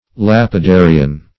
Lapidarian \Lap`i*da"ri*an\